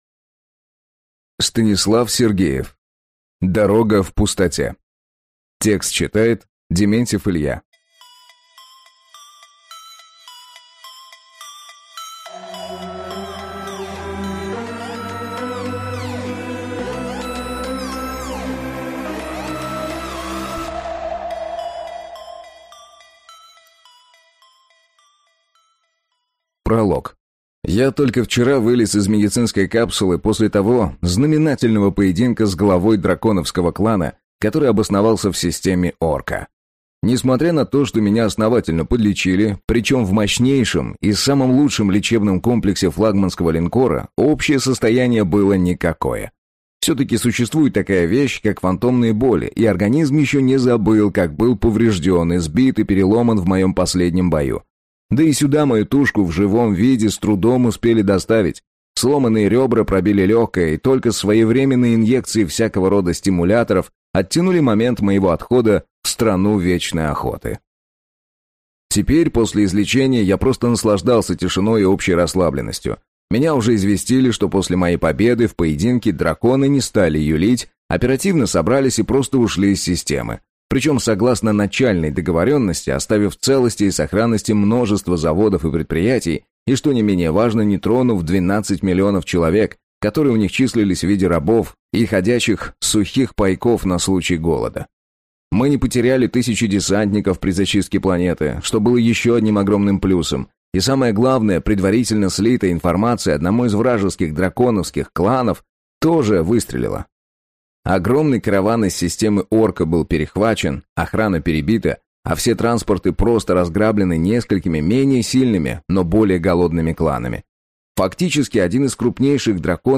Аудиокнига Солдаты Армагеддона: Дорога в пустоте | Библиотека аудиокниг